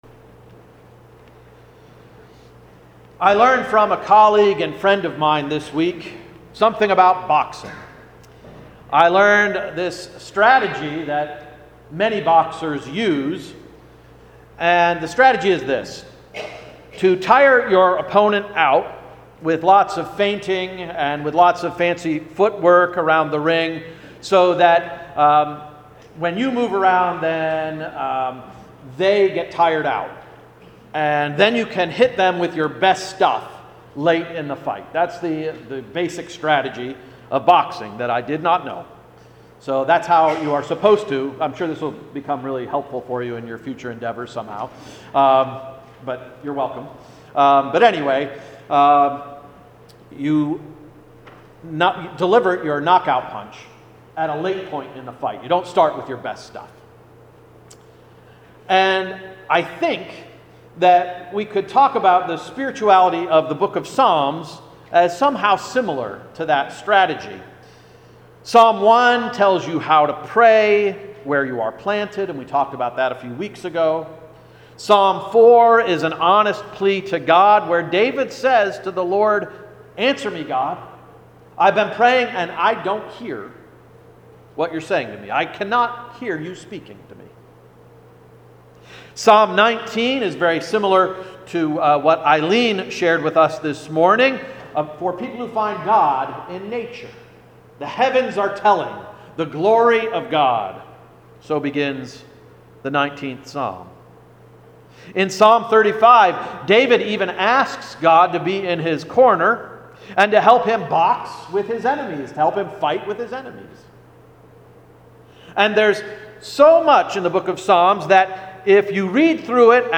August 26, 2018 Sermon — “Trust Fund”